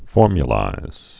(fôrmyə-līz)